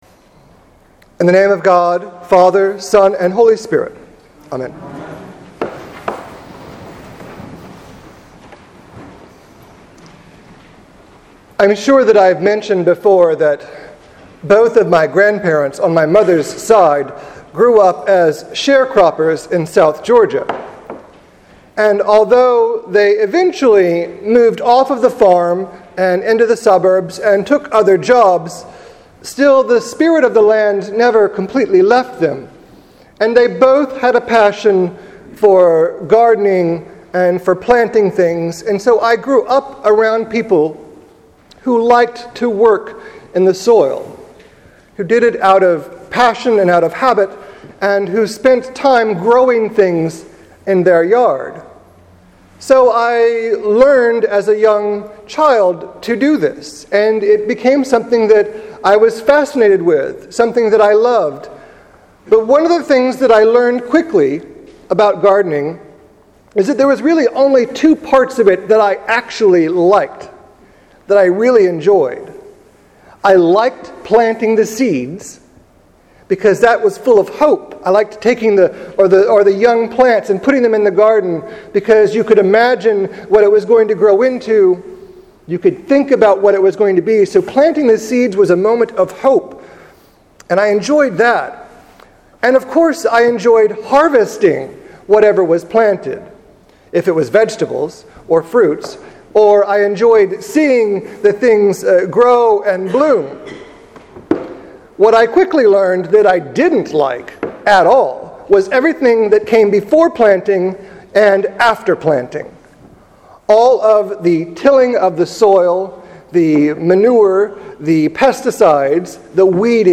Sermon for July 13, 2014: Growing Roots and Bearing Fruits